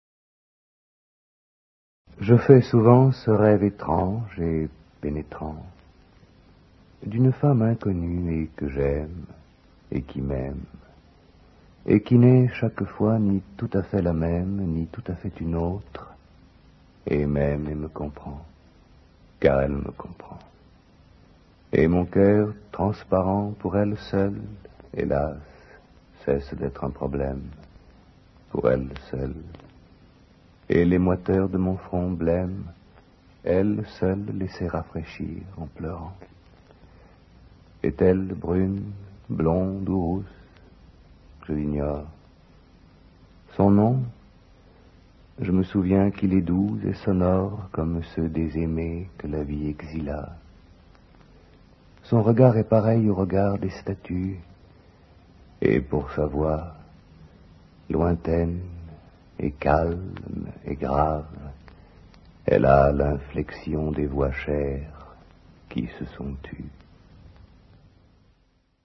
dit par François PÉRIER